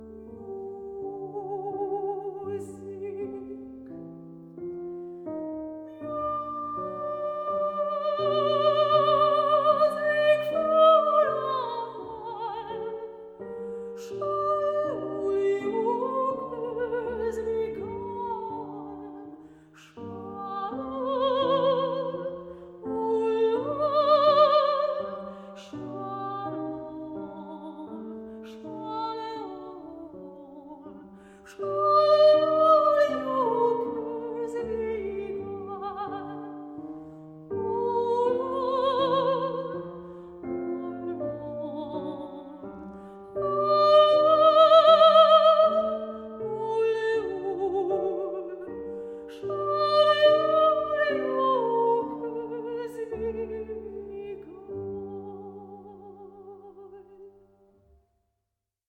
with every nuance beautifully portrayed.
VOCAL MUSIC
PIANO MUSIC